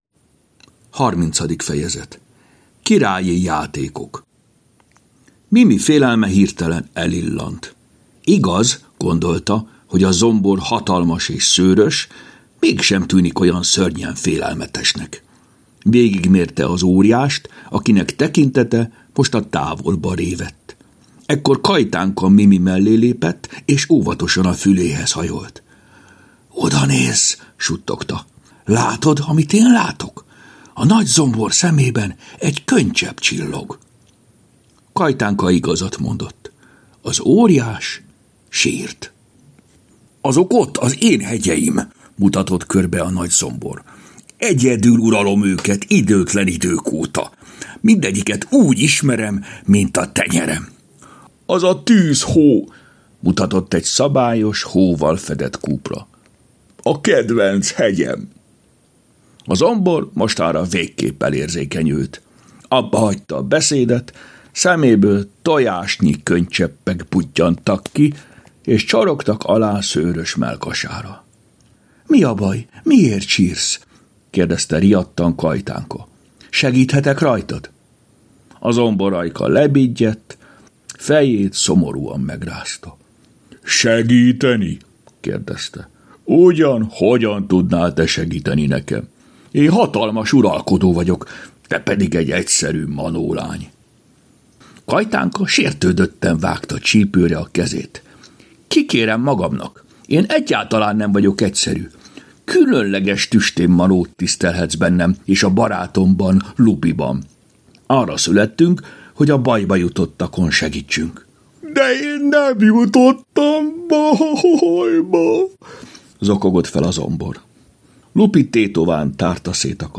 Hangos mese: Királyi játékok Mindet meghallgatom ebből a folyamból!